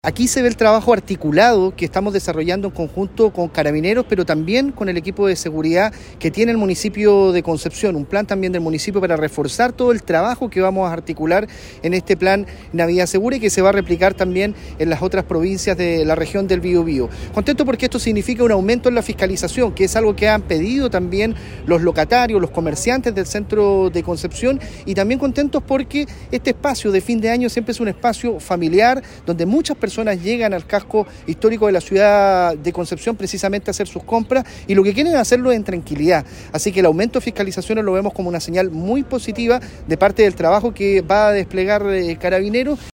En una ceremonia efectuada en la Plaza Independencia de Concepción, autoridades regionales hicieron entrega formal de 33 nuevos vehículos policiales que serán distribuidos en distintas comunas de la Región, y destacaron que estos recursos logísticos reforzarán la base de la labor policial, los patrullajes, y la operatividad general de Carabineros en el territorio.
En ese sentido, el delegado presidencial del Biobío, Eduardo Pacheco, destacó el trabajo coordinado con el municipio penquista en cuanto a la fiscalización, principalmente, en el centro de la ciudad y en línea con lo que han solicitado los gremios económicos y comerciales de la ciudad.